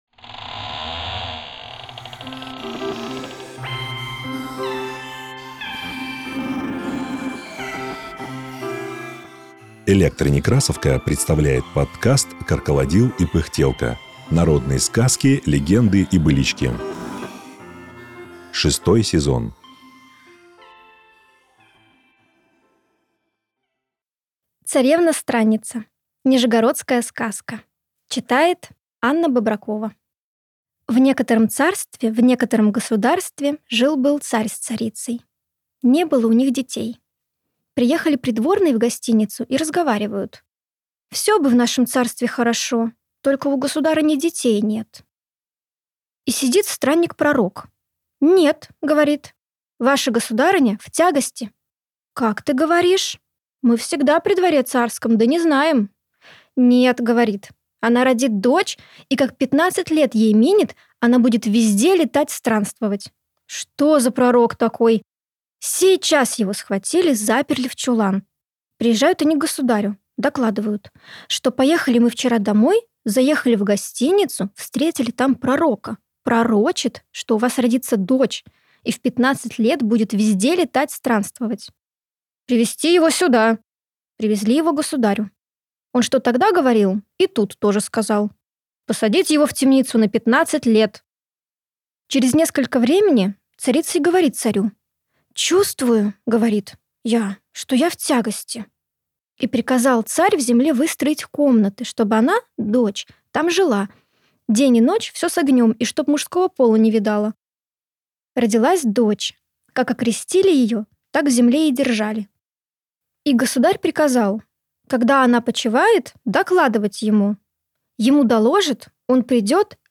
Это рассказы и очерки сибирских писателей о Братской ГЭС и первостроителях. Читают выпускники Школы-студии МХАТ, мастерской Виктора Рыжакова.